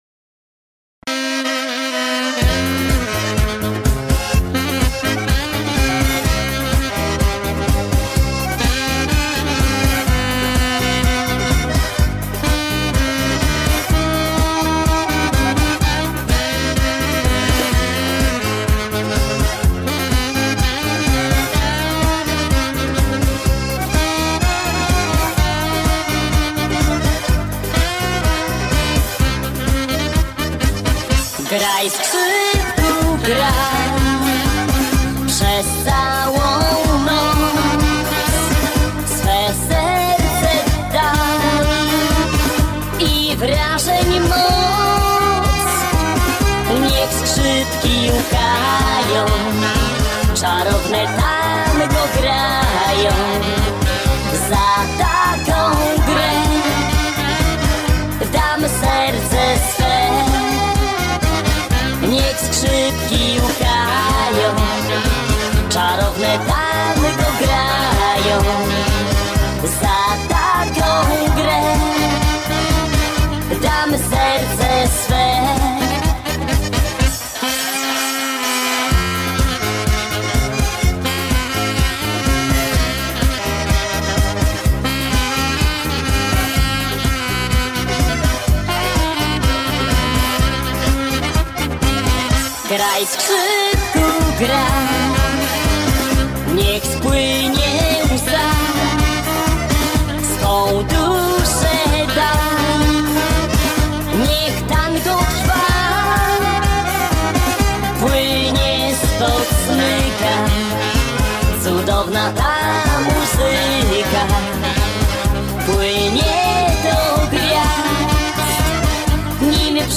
Старое польское танго.
В вопросе женское исполнение.